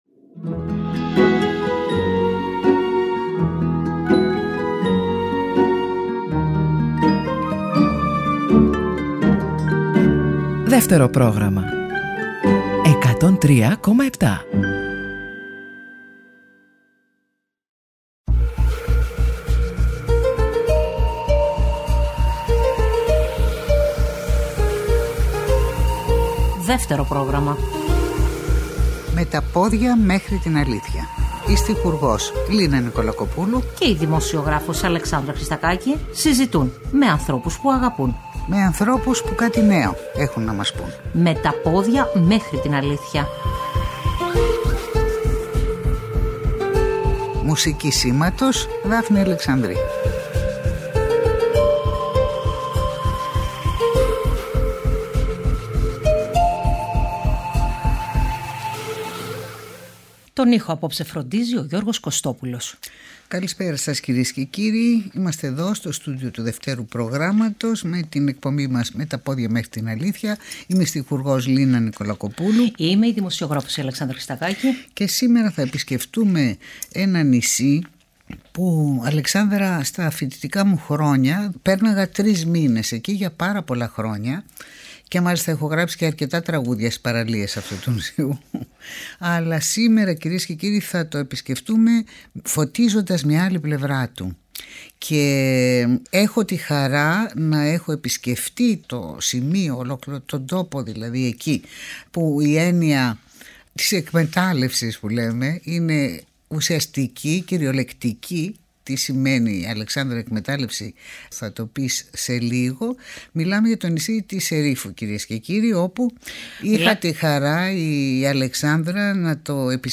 Στην εκπομπή ακούγονται ηχητικά ντοκουμέντα από τις συνεντεύξεις των πρώην μεταλλωρύχων